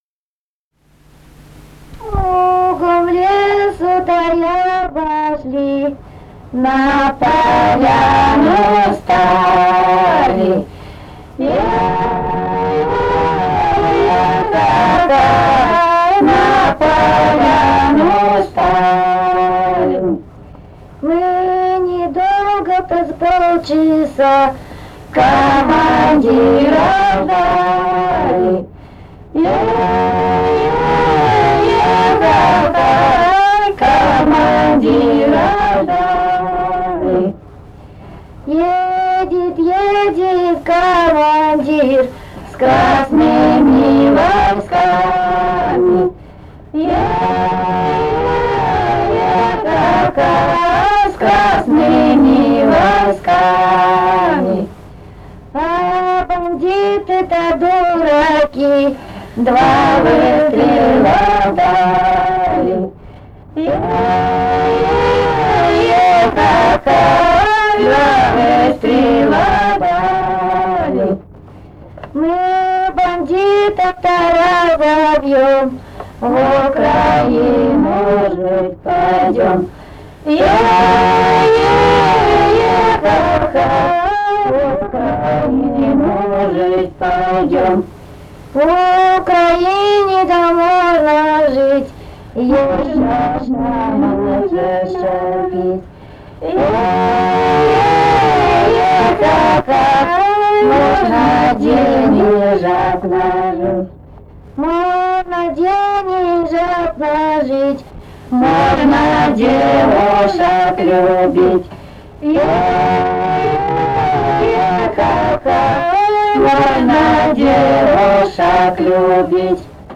Этномузыкологические исследования и полевые материалы
«Кругом лесу да обошли» (солдатская).
Алтайский край, с. Тигирек Краснощёковского района, 1967 г. И1019-13